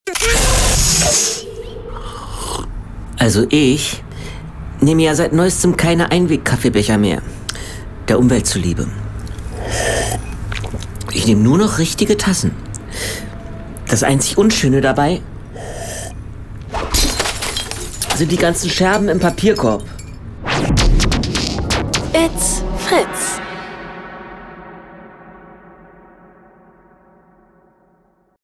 Comedy
Bei den Aufnahmen kamen keine echten Tassen zu Schaden.